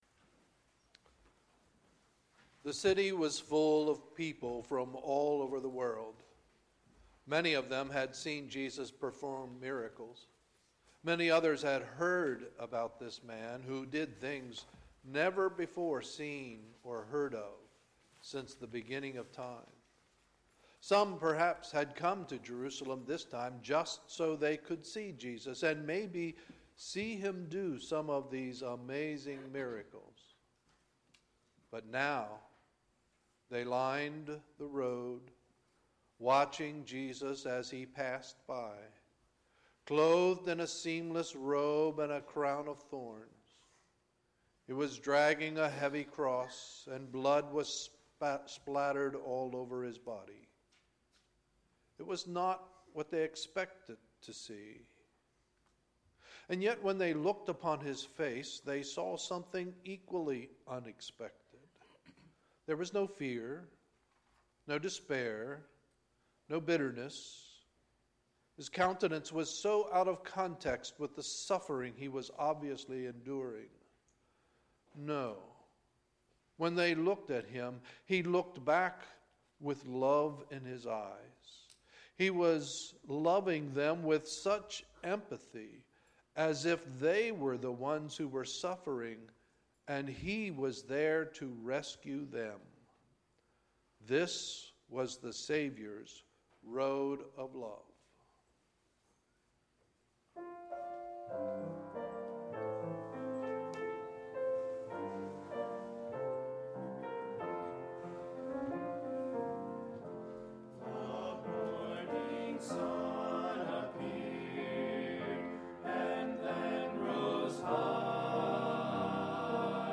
Sunday, April 21, 2019 – Sunday Morning Service
Sermons admin Resurrection Day Service with Music and Message North Hills Bible Church